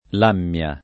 lammia [ l # mm L a ] → lamia